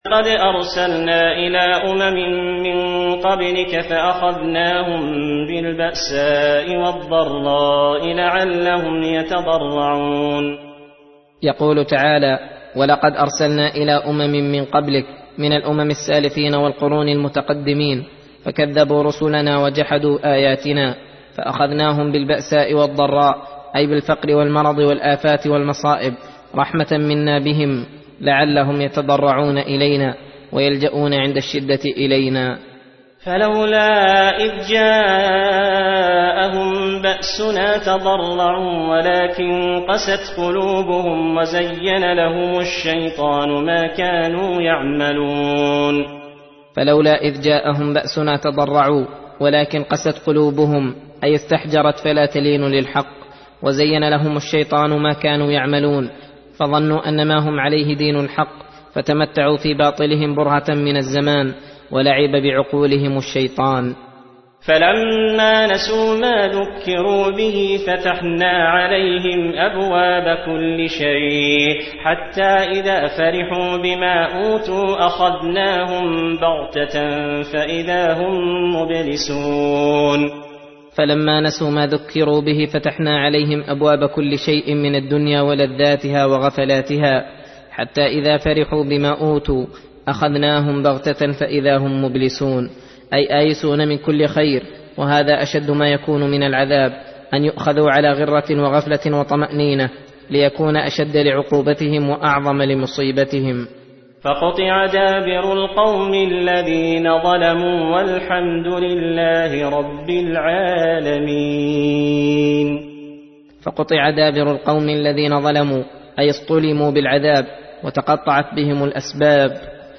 درس (27) : تفسير سورة الأنعام : (42-59)